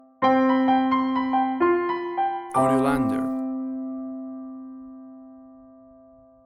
WAV Sample Rate: 16-Bit stereo, 44.1 kHz